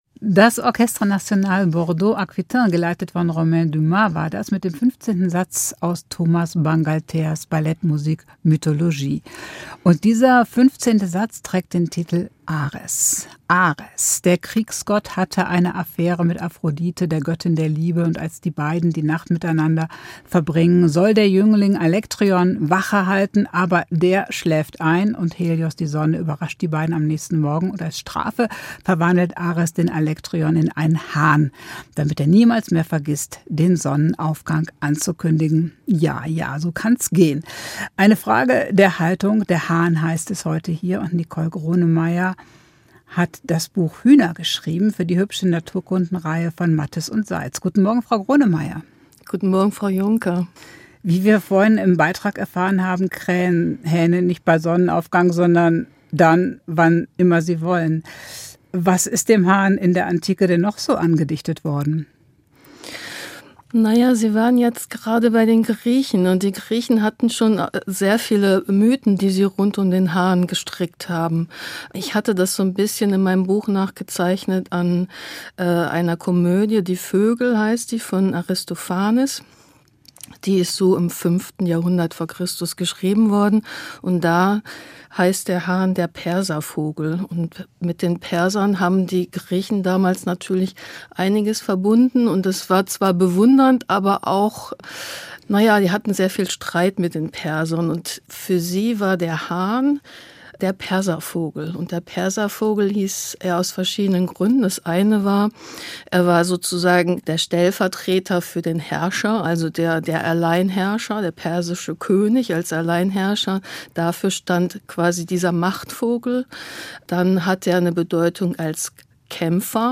Ein inspirierendes Gespräch darüber, wie Tiere und Menschen gut zusammenleben können.